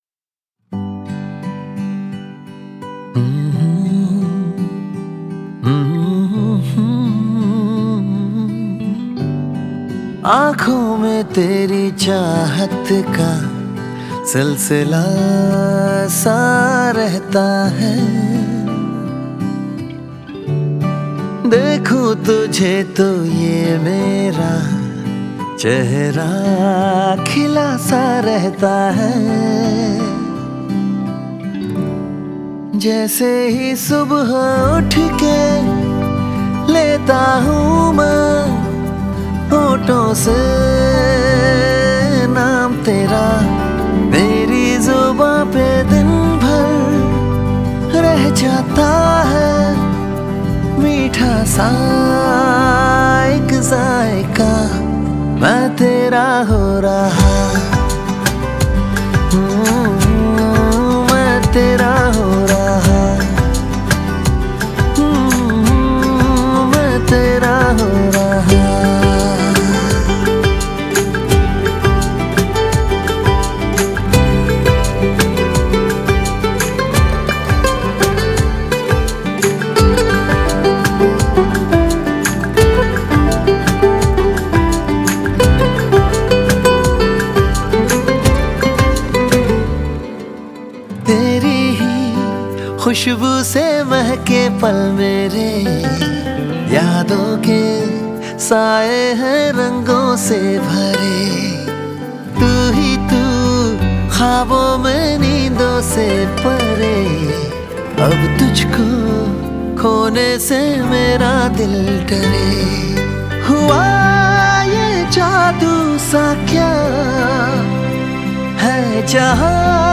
IndiPop